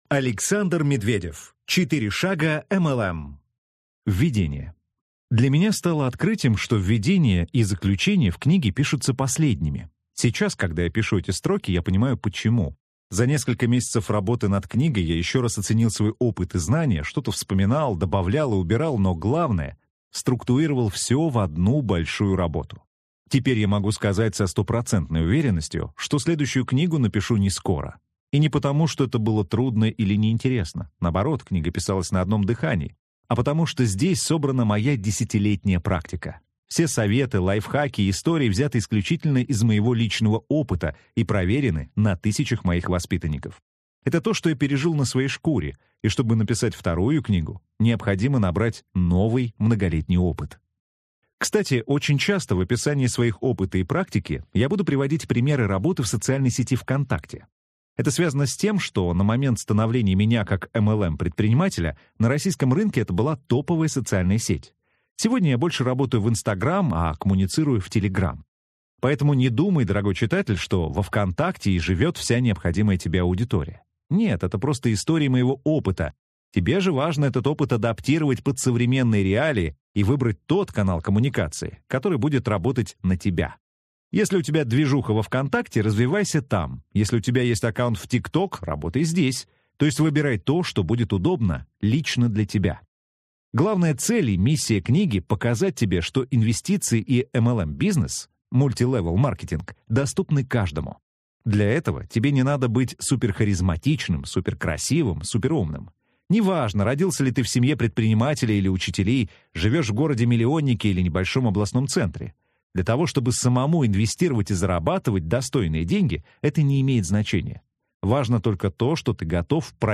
Аудиокнига 4 шага МЛМ | Библиотека аудиокниг